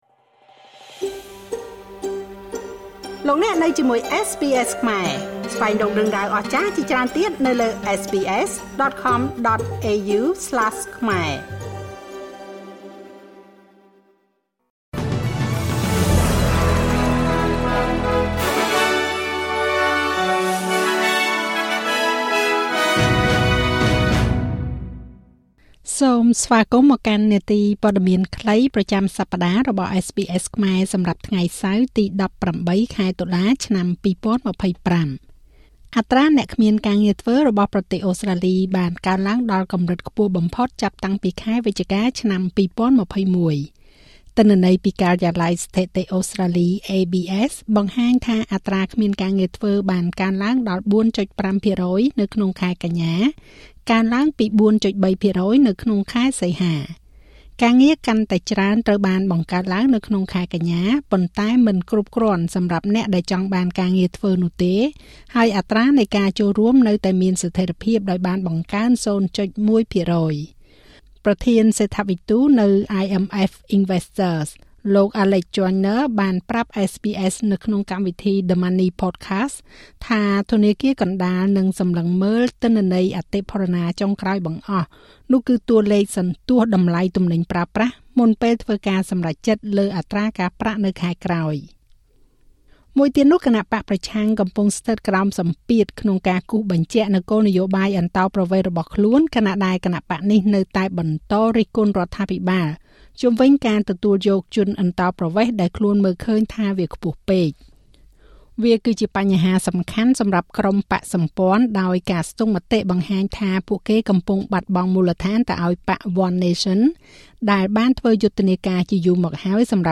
នាទីព័ត៌មានខ្លីប្រចាំសប្តាហ៍របស់SBSខ្មែរ សម្រាប់ថ្ងៃសៅរ៍ ទី១៨ ខែតុលា ឆ្នាំ២០២៥